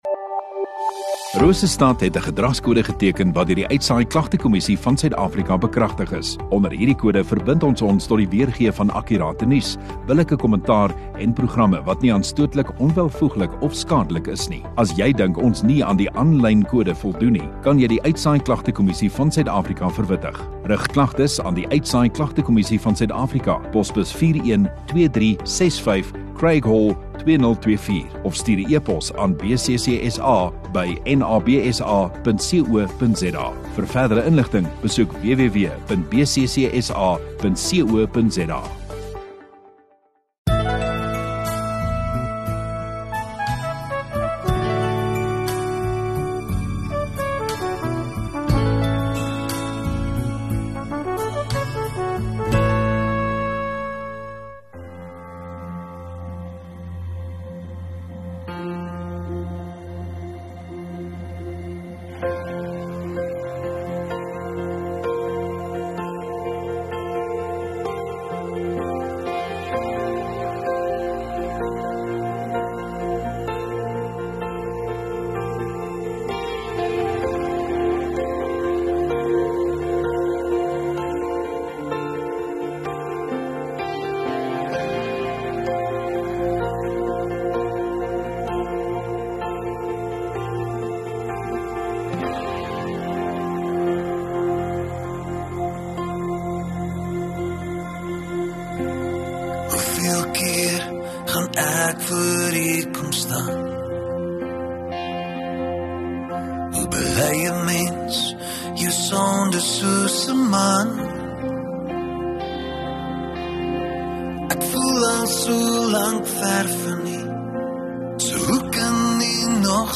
29 Jun Saterdag Oggenddiens